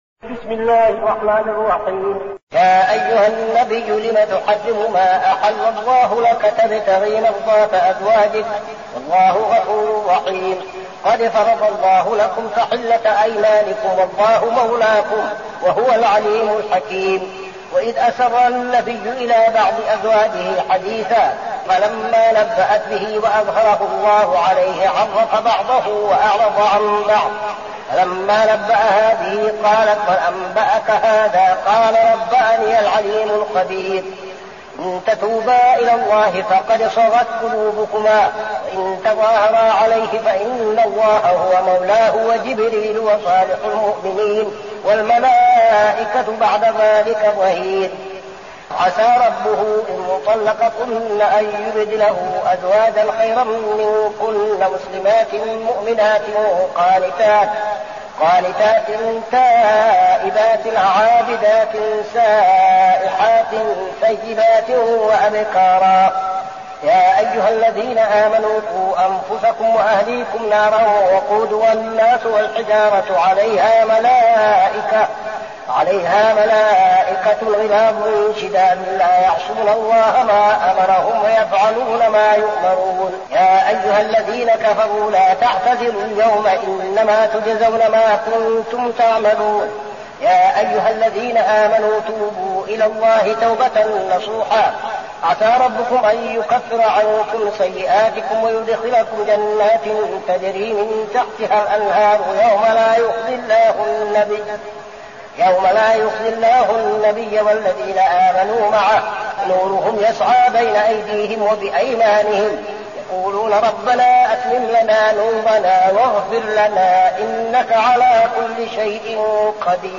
المكان: المسجد النبوي الشيخ: فضيلة الشيخ عبدالعزيز بن صالح فضيلة الشيخ عبدالعزيز بن صالح التحريم